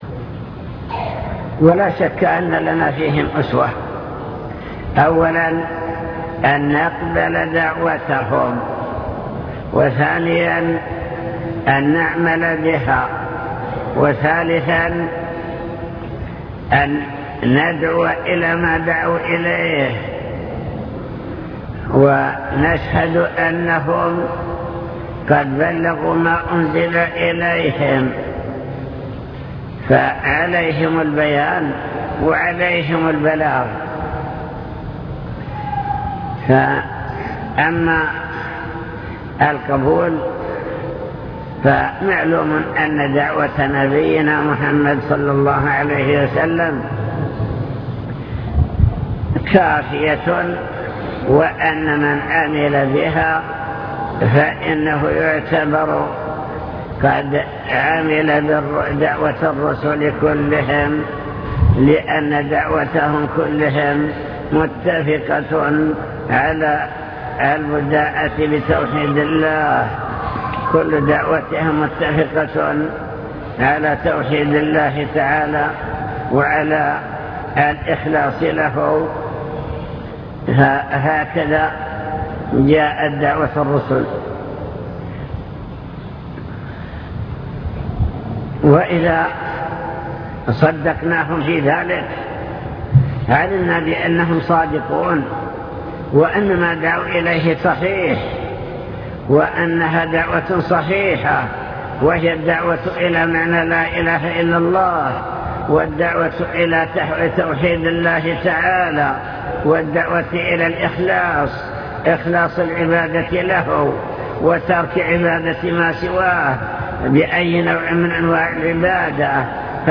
المكتبة الصوتية  تسجيلات - محاضرات ودروس  محاضرة في جامع حطين دعوة الأنبياء والرسل